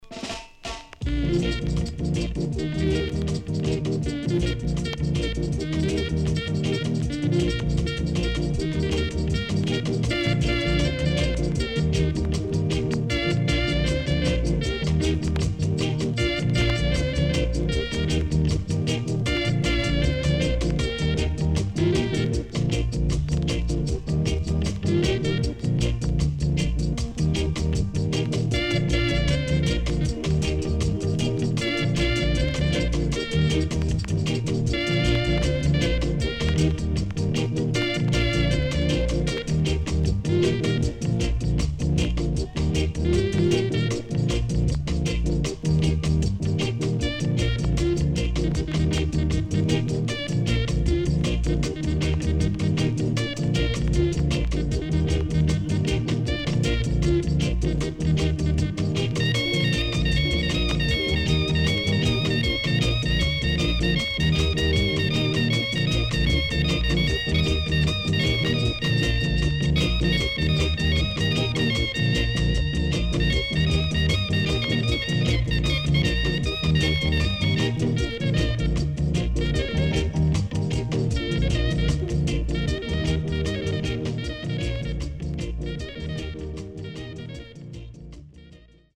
HOME > REGGAE / ROOTS  >  EARLY REGGAE  >  INST 70's
SIDE A:所々チリノイズがあり、少しプチノイズ入ります。